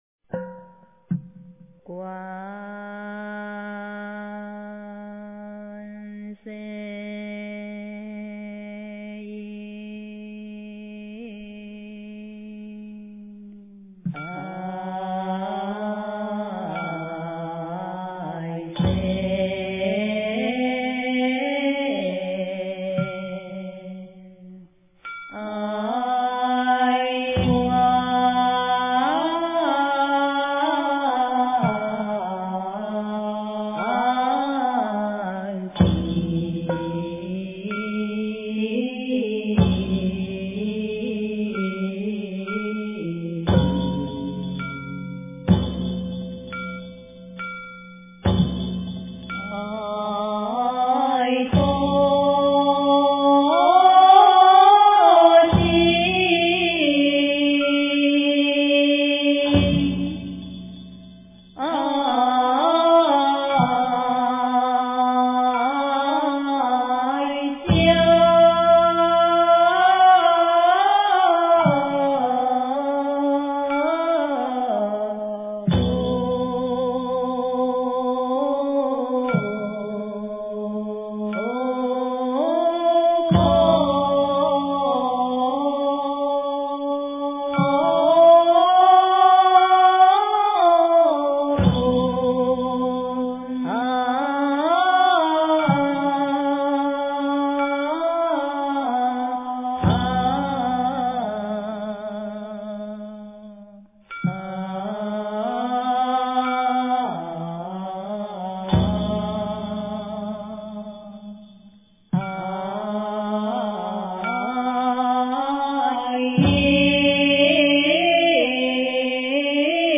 经忏
佛音
佛教音乐